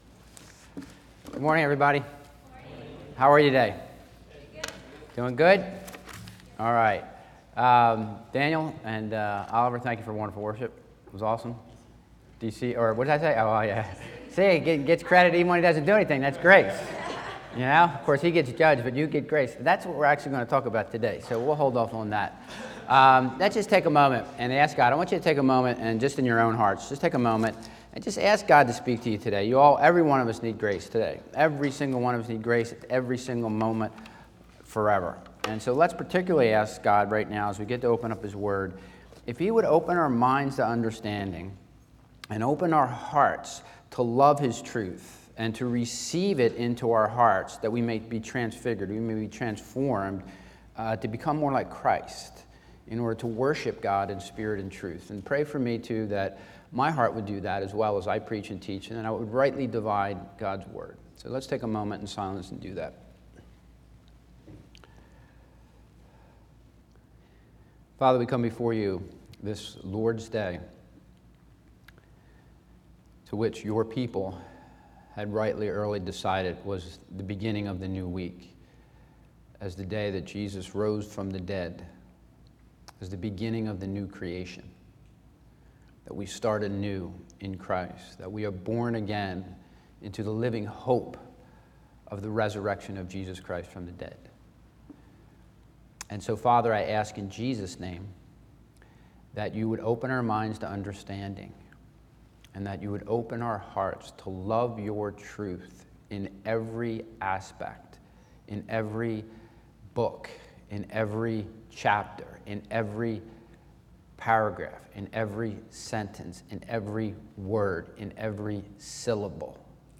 | CBCWLA English Congregation